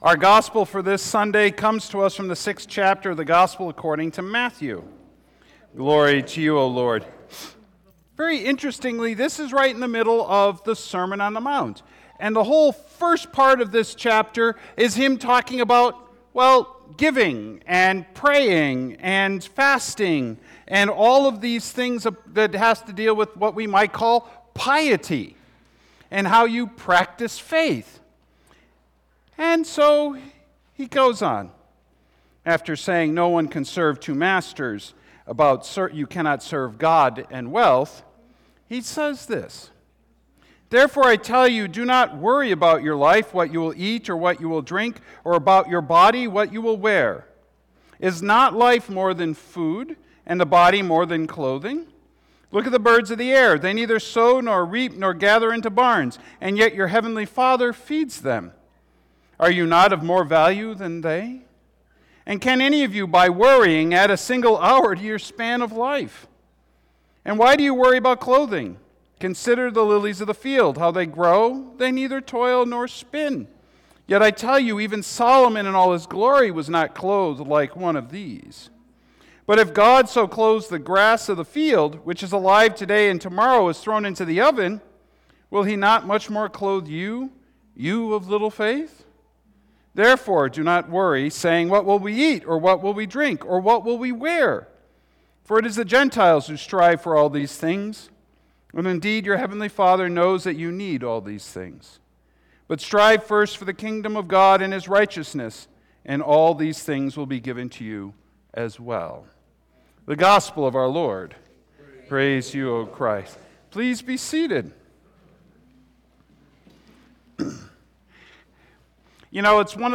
Sermons | Beautiful Savior Lutheran Church
Blessing of the Animals